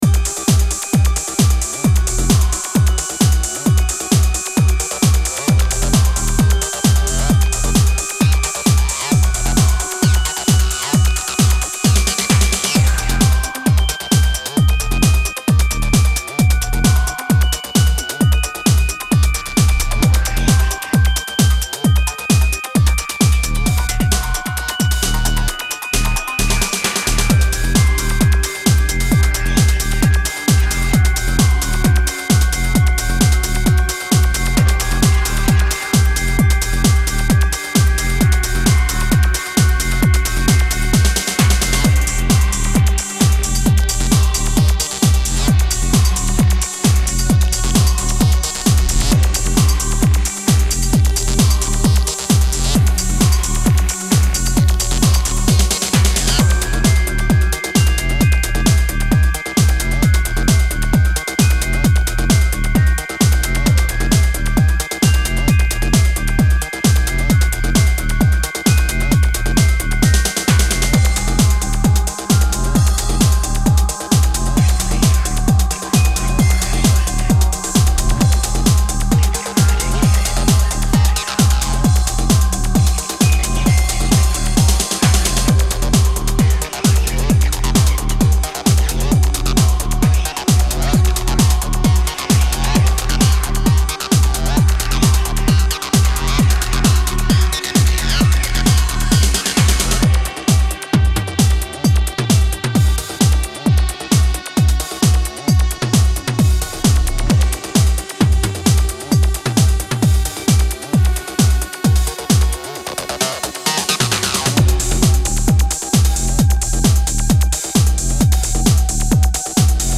dark flavour